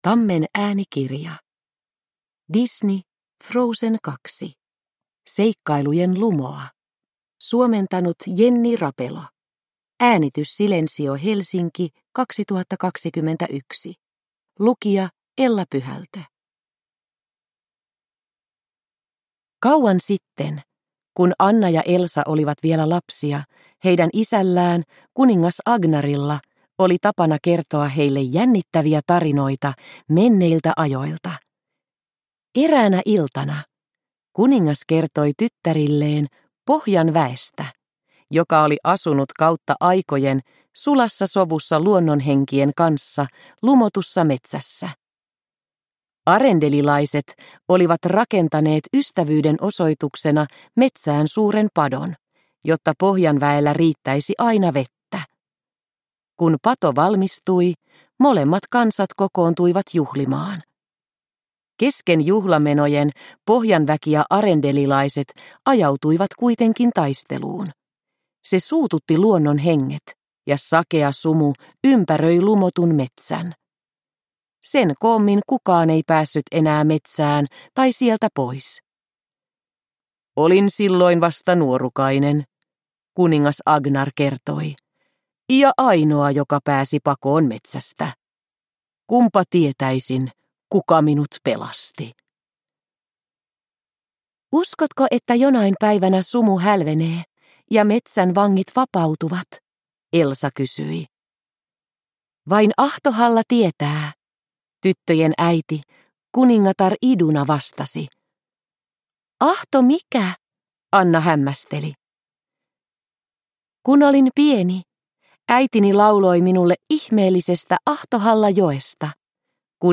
Frozen 2. Seikkailujen lumoa – Ljudbok – Laddas ner